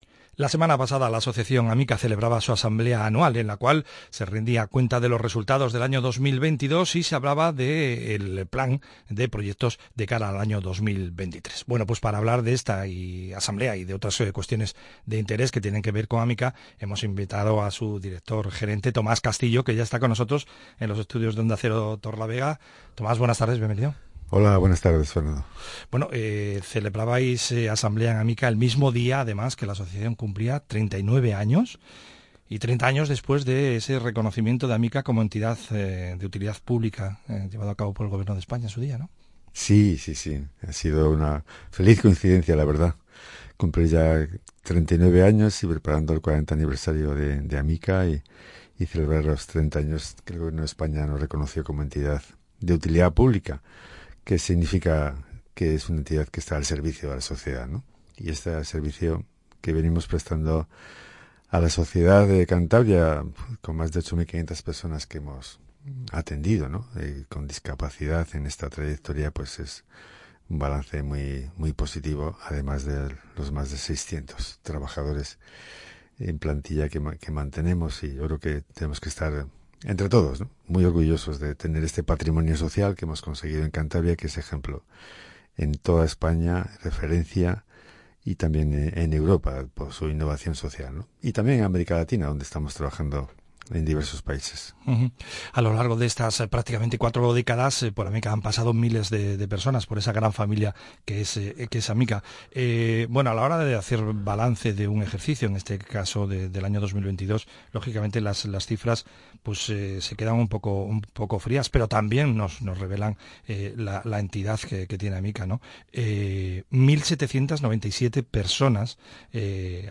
Entrevista en Onda Cero Torrelavega